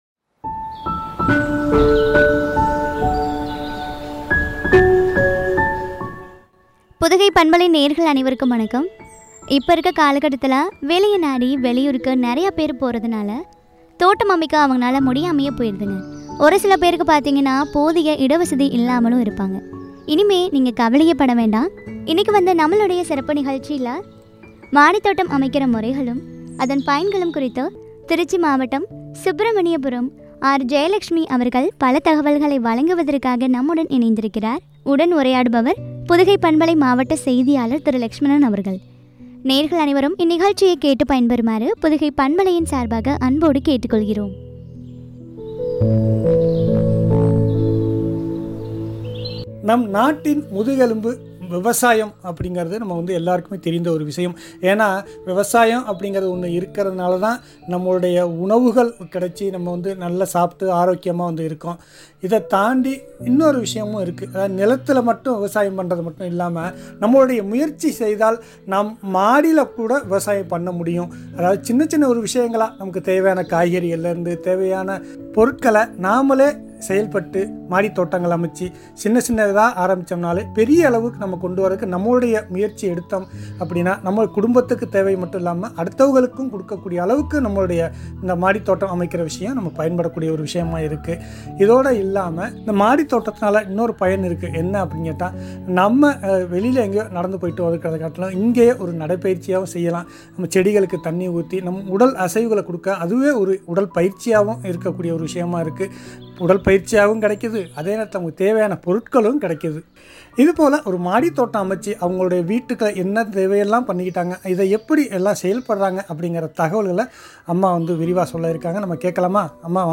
மாடித்தோட்டம் அமைக்கும் முறைகளும், பயன்களும் பற்றிய உரையாடல்.